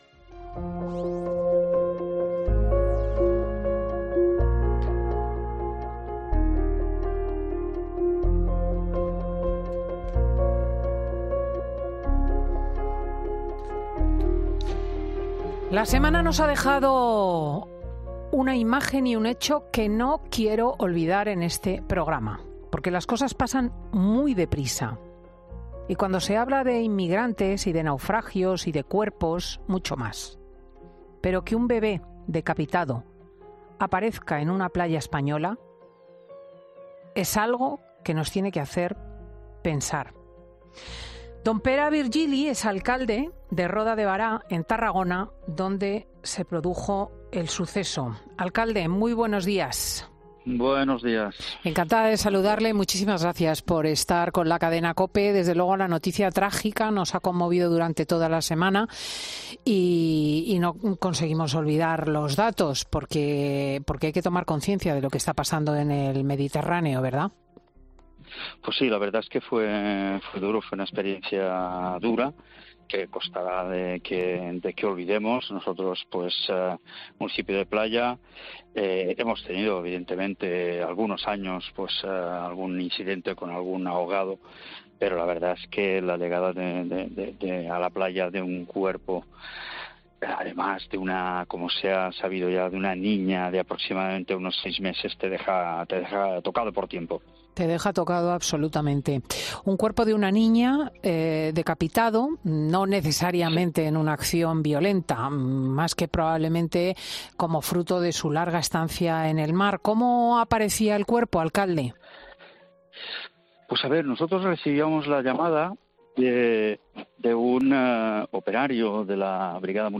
En 'Fin de Semana' hablamos con Pere Virgili Domínguez, alcalde de Roda de Bará que explicaba cómo encontraron a la niña y cuánto tiempo pudo estar...